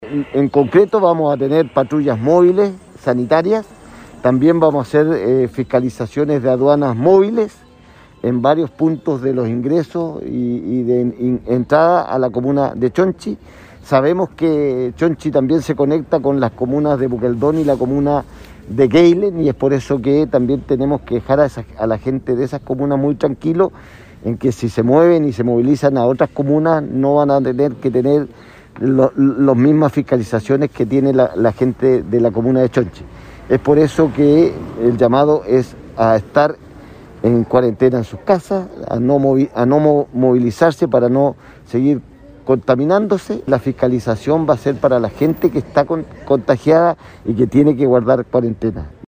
El gobernador Fernando Bórquez expresó que también habrá un trabajo de aduanas que en distintos puntos de conexión terrestre con las otras comunas, inspeccionarán que se cumpla la medida.